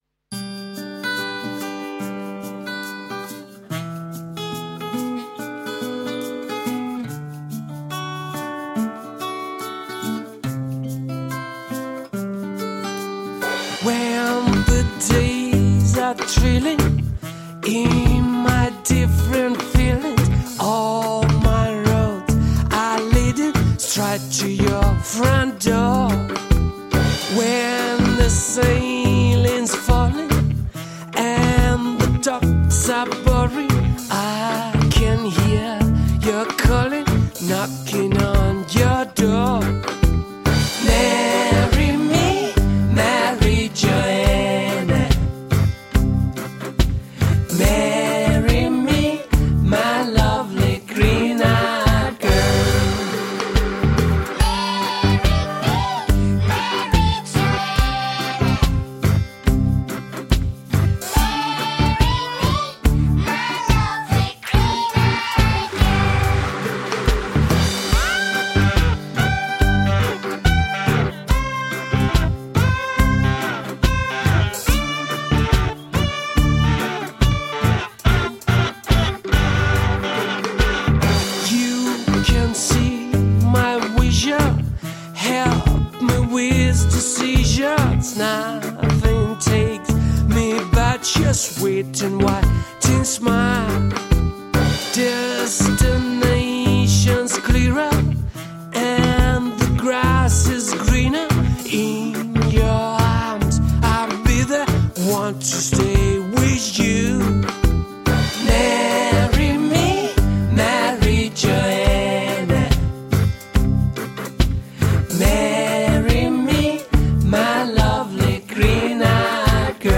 Tagged as: Alt Rock, Pop, Classic rock, Prog Rock